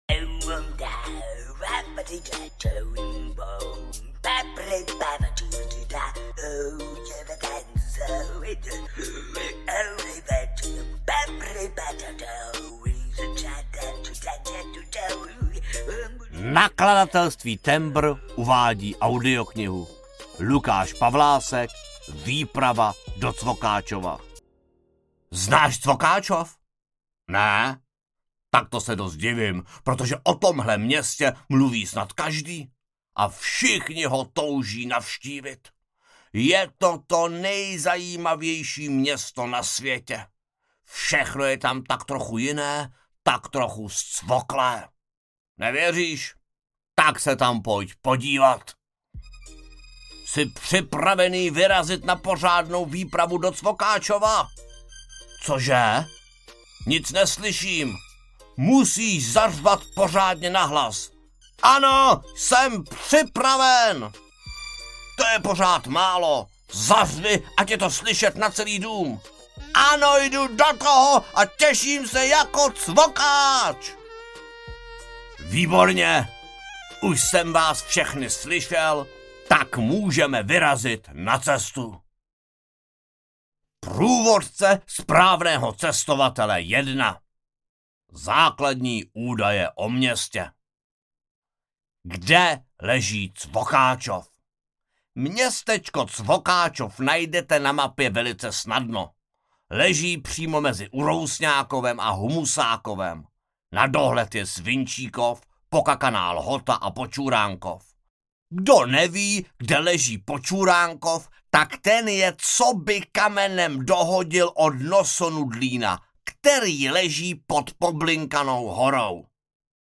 Výprava do Cvokáčova audiokniha
Ukázka z knihy
vyprava-do-cvokacova-audiokniha